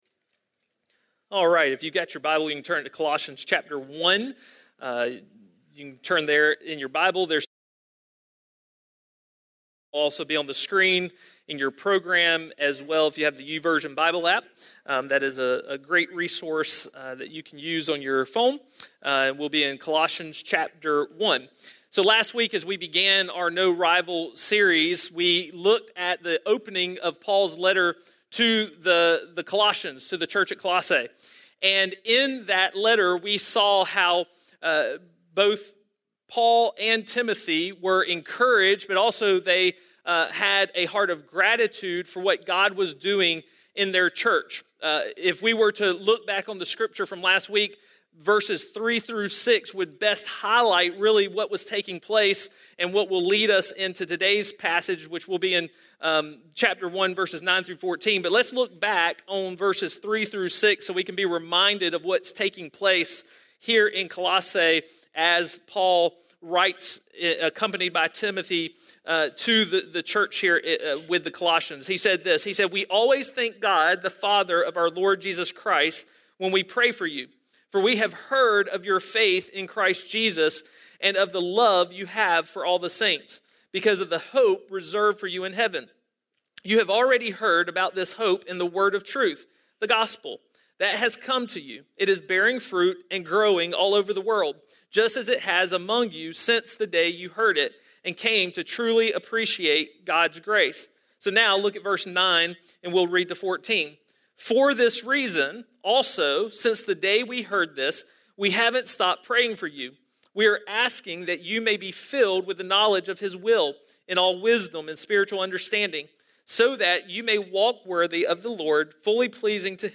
A message from the series "Unhindered."